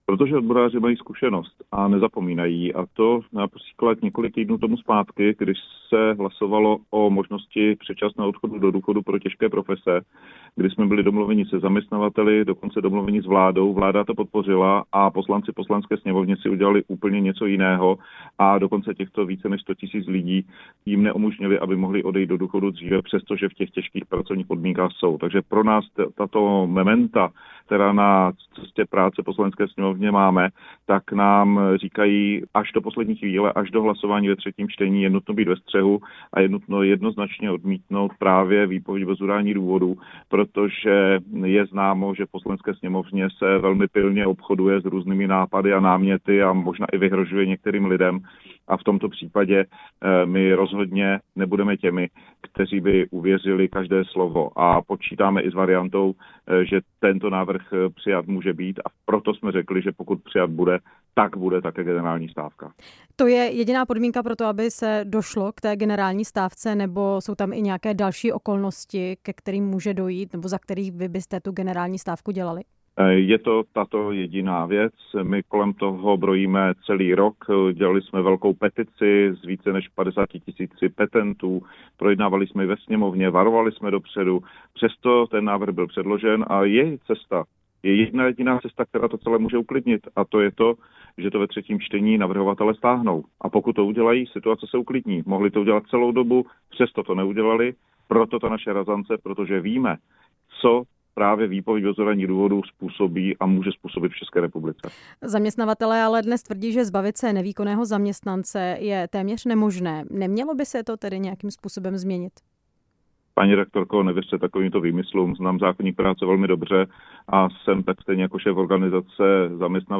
Proč jsou odboráři přesto ve stávkové pohotovosti? Hostem vysílání Radia Prostor byl předseda Českomoravské konfederace odborových svazů Josef Středula, který se k situaci vyjádřil.